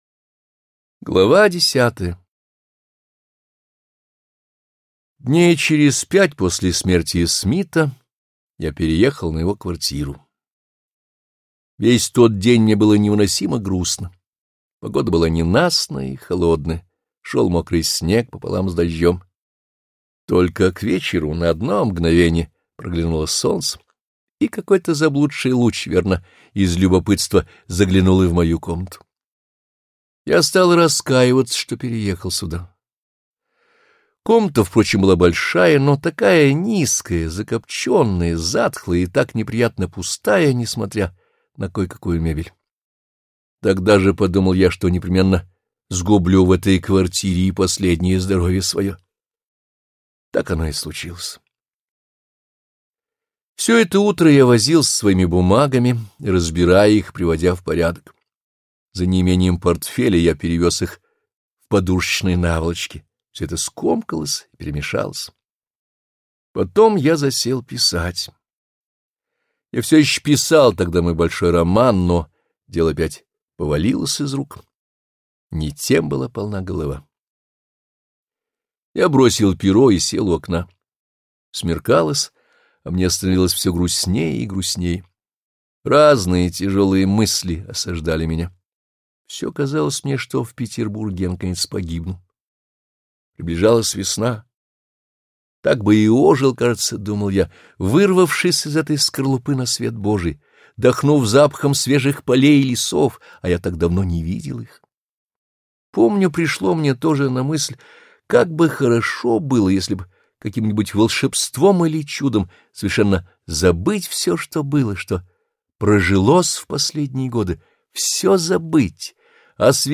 Аудиокнига Униженные и оскорблённые | Библиотека аудиокниг